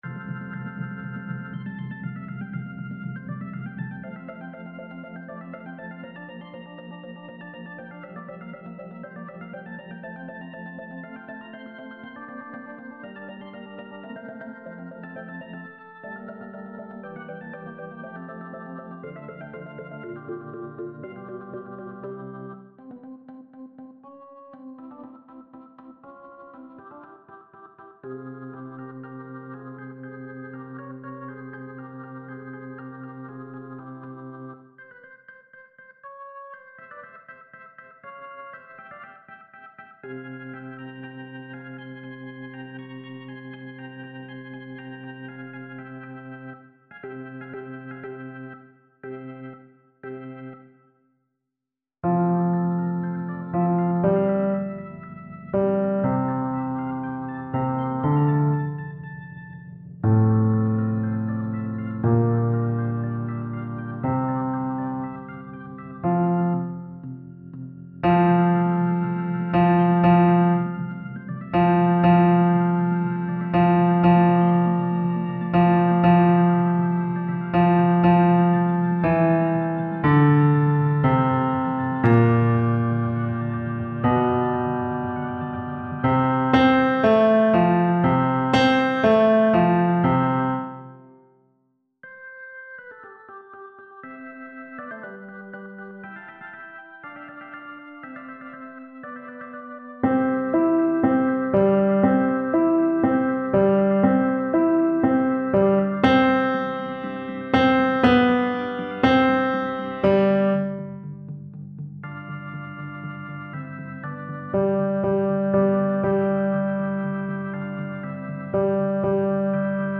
Fichiers de Travail des Basses